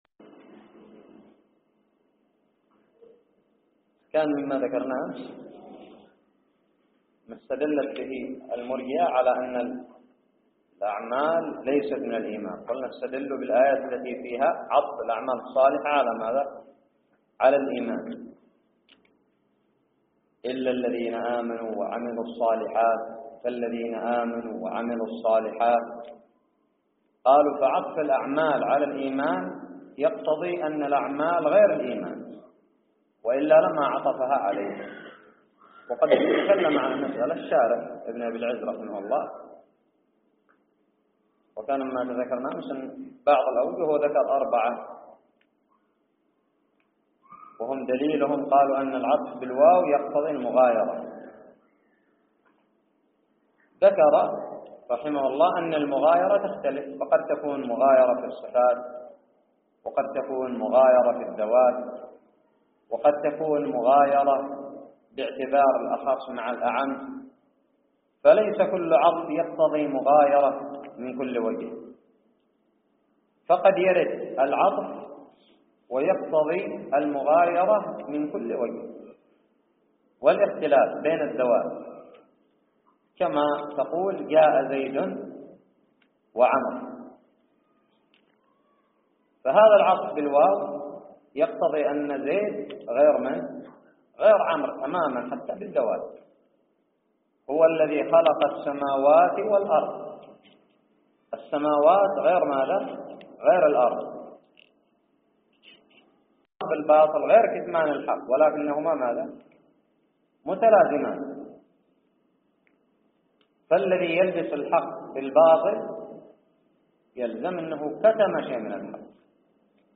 الدرس الحادي والثلاثون من شرح العقيدة الطحاوية
ألقيت في دار الحديث بدماج